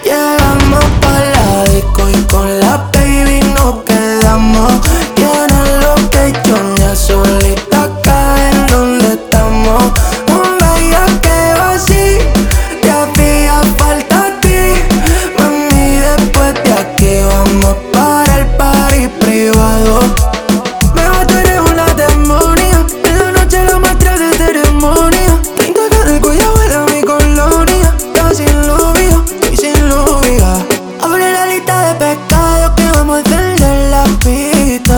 Жанр: Поп музыка / Латино